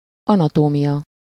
Ääntäminen
Synonyymit bonctan Ääntäminen Tuntematon aksentti: IPA: /ˈɒnɒtoːmiʲɒ/ IPA: /ɒ.nɒ.toː.mjɒ/ Haettu sana löytyi näillä lähdekielillä: unkari Käännös Ääninäyte Substantiivit 1. anatomy US Luokat Anatomia Tieteet